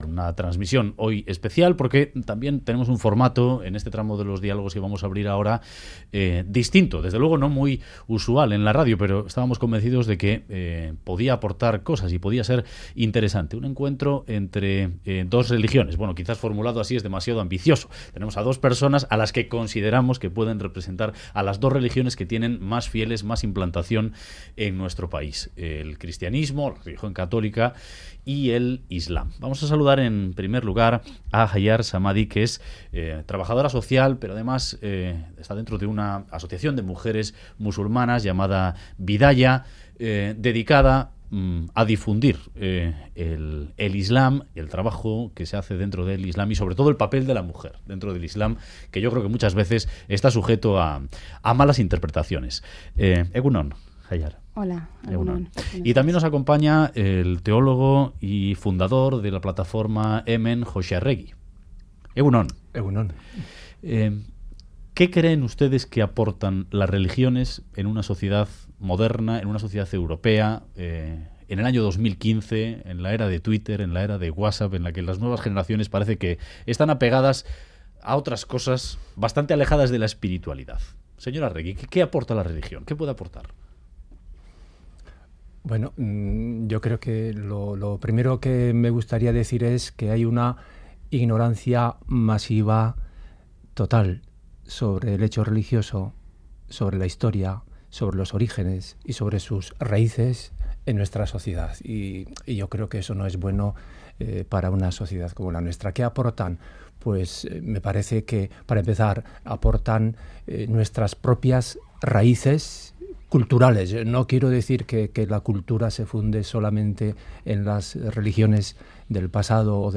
Encuentro en Radio Euskadi